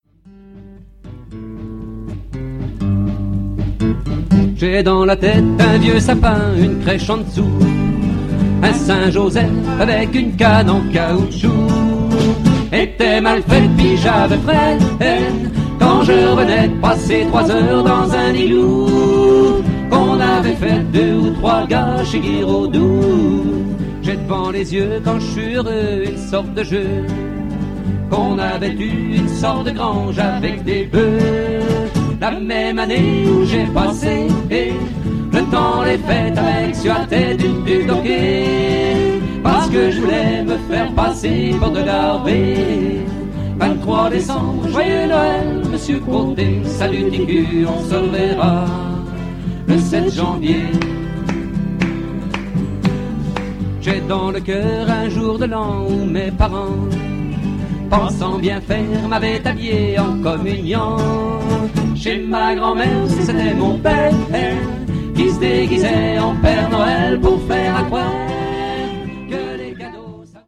Quelques morceaux ou extraits enregistrés sur scène :